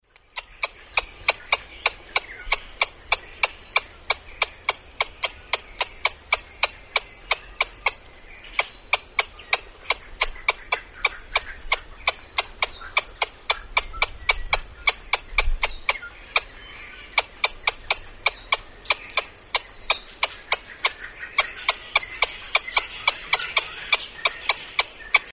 Звуки белки
Послушайте разные звуки белок в хорошем качестве mp3